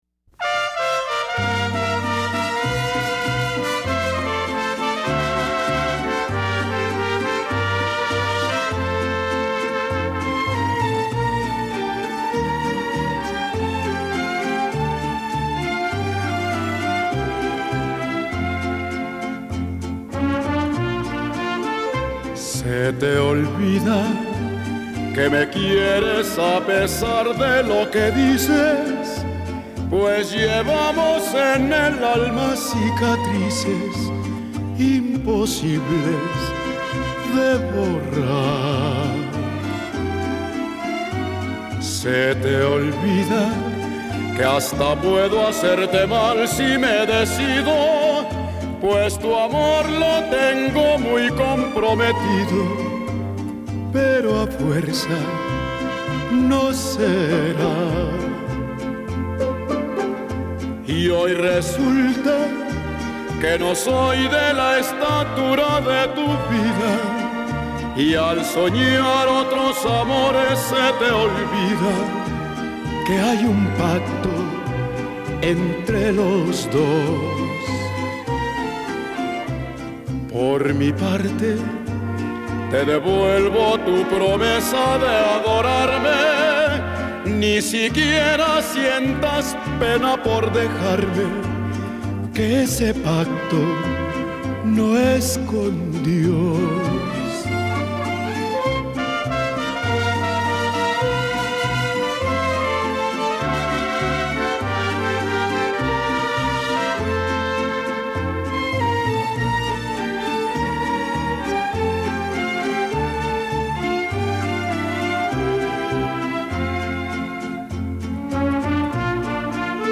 bolero ranchero.
¿El mariachi?